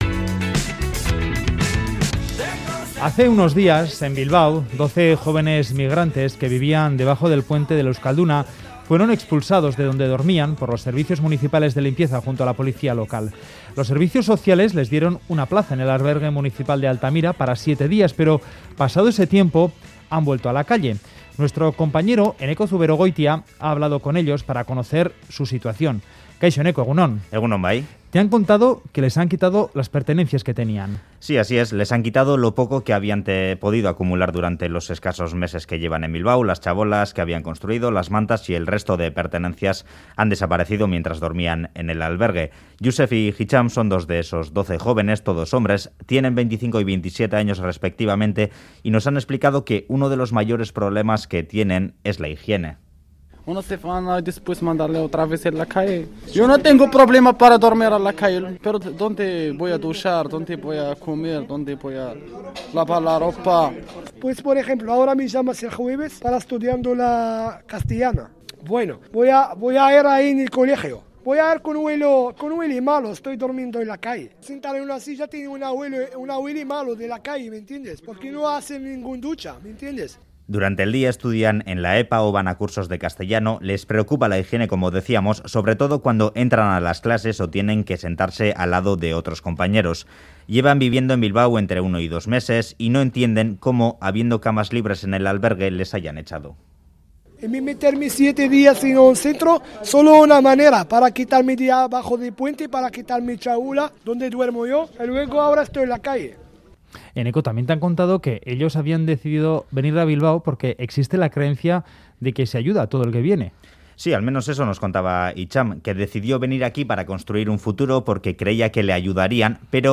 Audio: Migrantes sin hogar reportaje